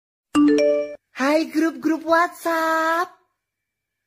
Nada Notifikasi ‘Hai Group WhatsApp’
Genre: Nada notifikasi Tag: nada notifikasi Ukuran file: 58 KB Dilihat: 107 Views / 6 Downloads Detail: Bikin notifikasi grup WA kamu makin seru dengan nada "Hai" yang keren dan unik!
Suaranya simpel tapi langsung bikin perhatian — pas banget buat chat grup keluarga, teman, atau kerjaan.
nada-notifikasi-hai-group-whatsapp.mp3